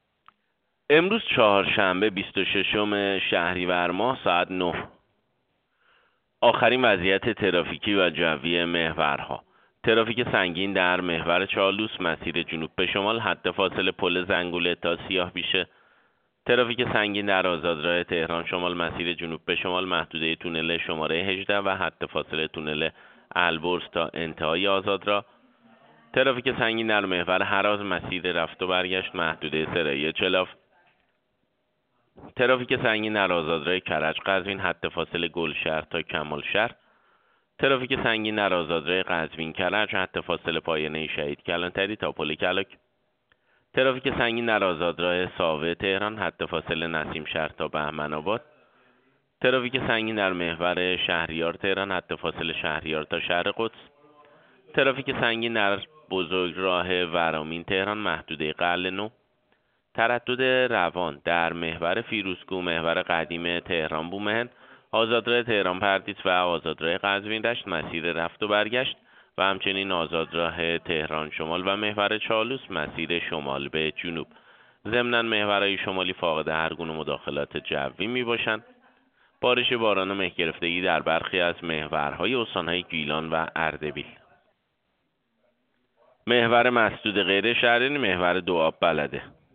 گزارش رادیو اینترنتی از آخرین وضعیت ترافیکی جاده‌ها ساعت ۹ بیست و ششم شهریور؛